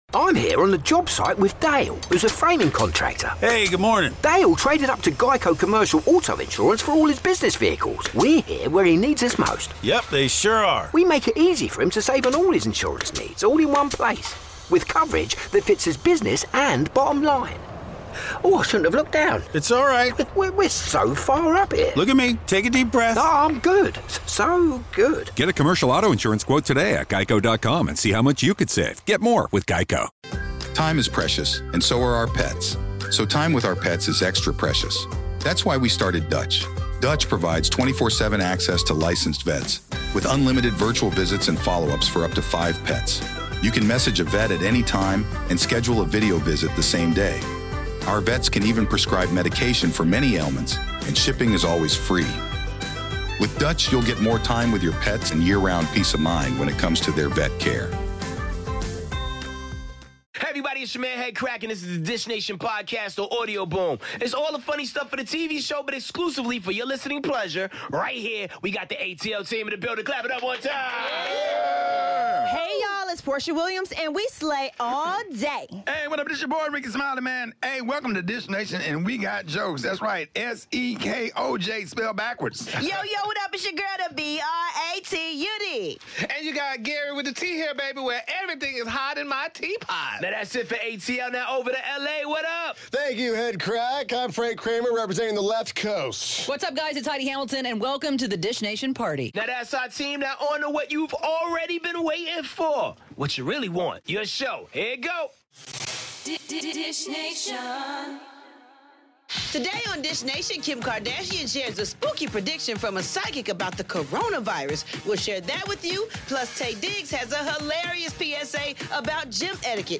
Jessica Simpson has no regrets, Bellamy Young swings by the LA studio to dish on 'Prodigal Son' and we’re gettin' thirsty with 'Thirst Trapping With The Stars' on today's Dish Nation!